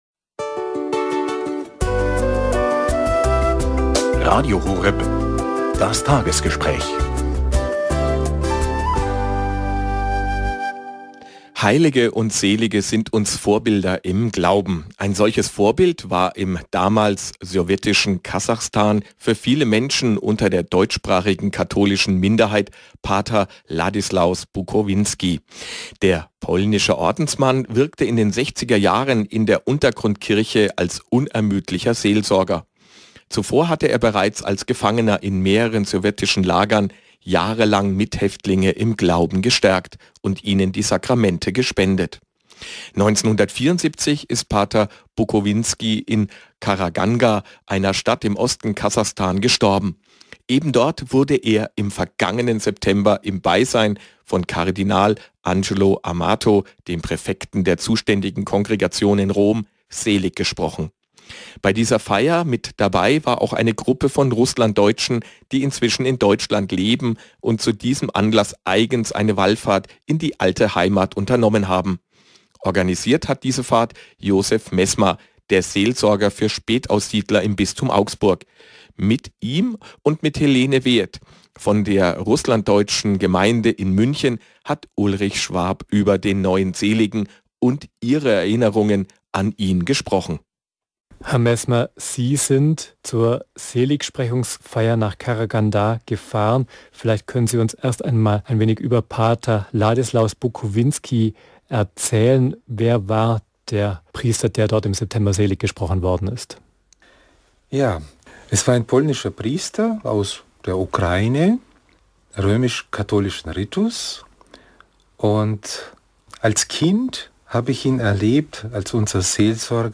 9. März 2017 Interview beim Radio „Horeb“
Auch dieses Jahr, 24.02.2017 fand ein Interview statt.